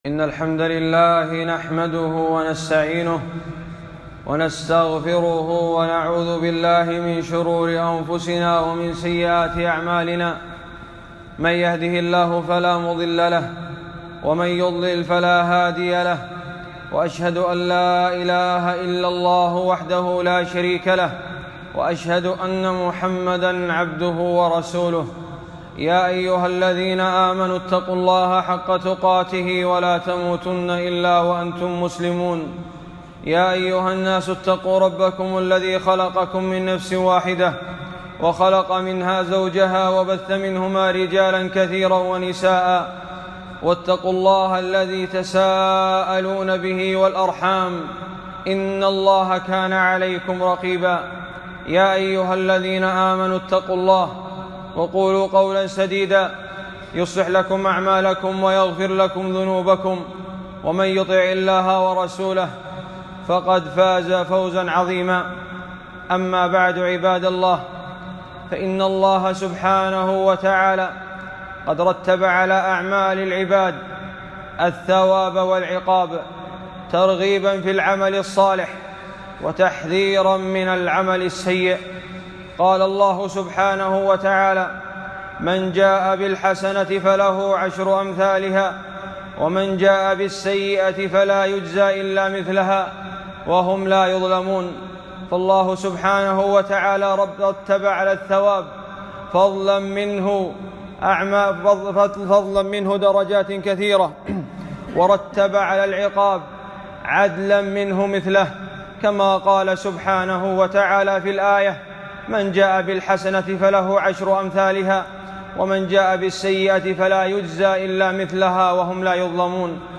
خطبة - أعمال صالحات ثوابها من جنسها